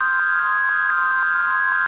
rainmove.mp3